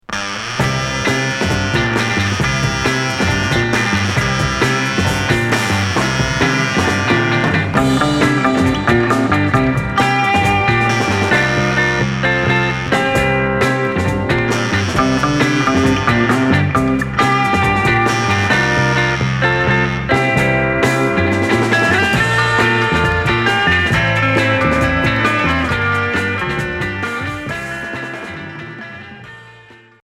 Jerk à fuzz